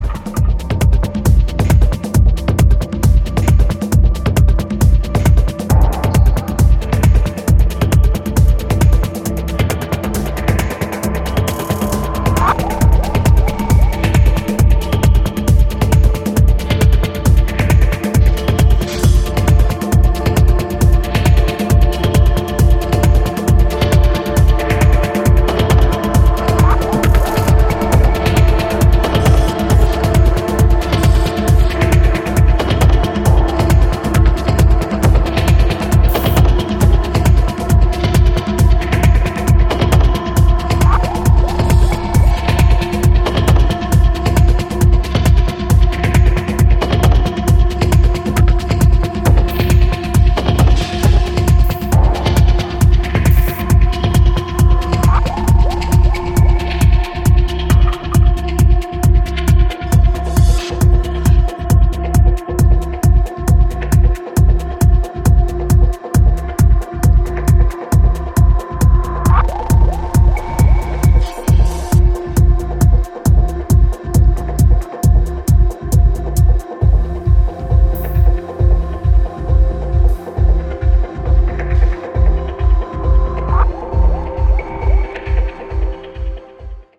ゴツゴツとしたトライバルなドラムがパウンドするダブ・テクノ